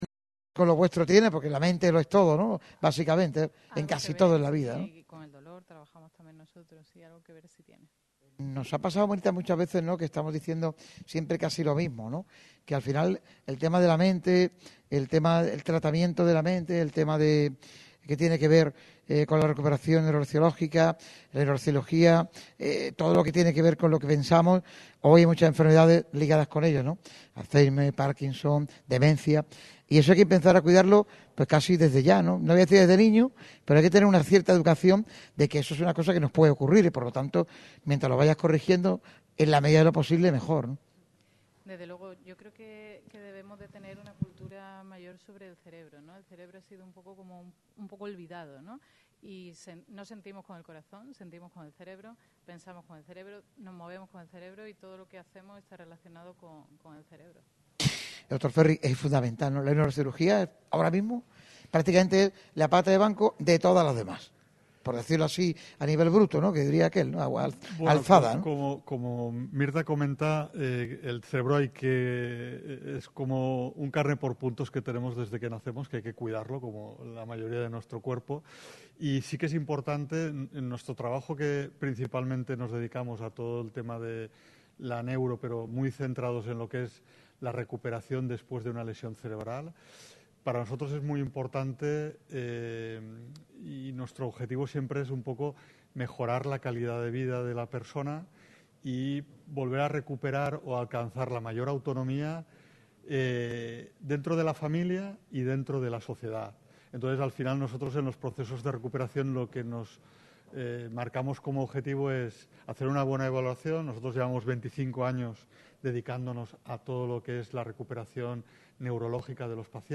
Radio MARCA Málaga ha hecho un programa especial desde las instalaciones del Hospital Vithas Internacional de Benalmádena, donde se ha repasado los avances de una empresa dedicada en cuerpo y alma a la salud.